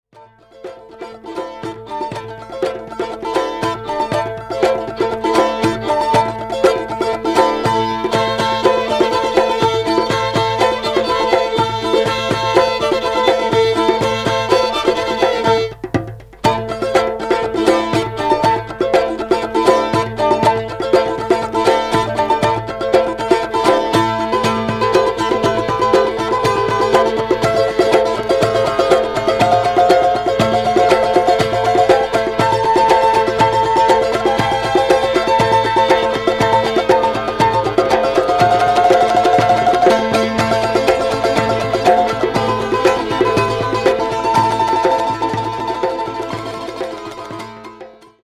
即興　霊性　地中海